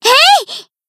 BA_V_Mika_Battle_Shout_2.ogg